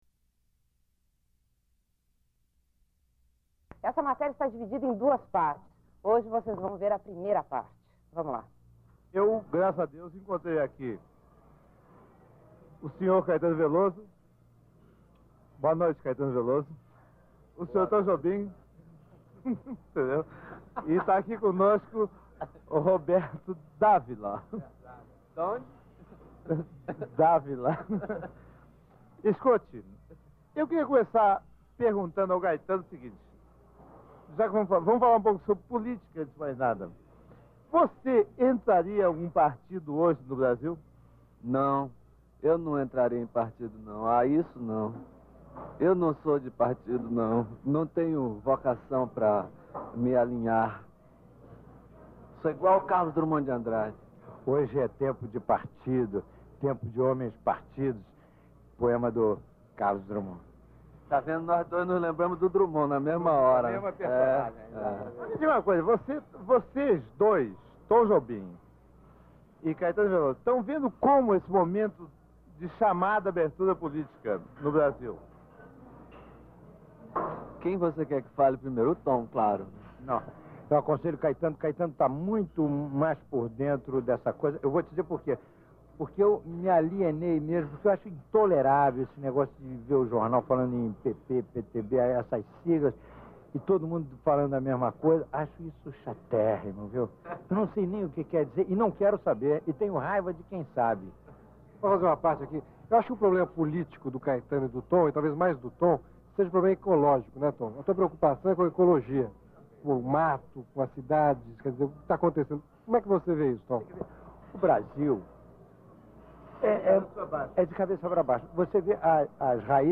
Author: Antonio Carlos Jobim | Caetano Veloso Author: Antonio Carlos Jobim | Caetano Veloso | [Tarso de Castro] | Roberto D'Ávila Date: 1982-1985 Abstract: Além da entrevista, há uma sequência de músicas de artistas e intérpretes variados. Type: Entrevista